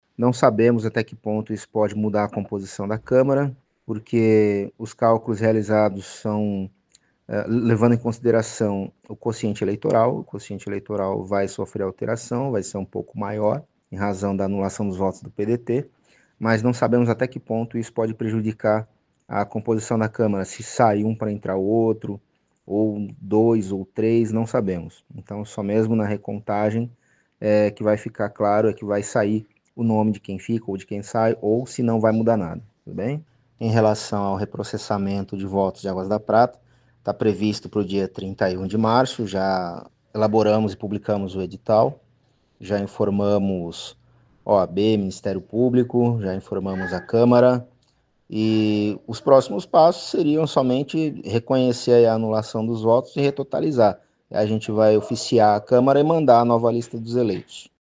concedeu uma entrevista exclusiva à 92FM São João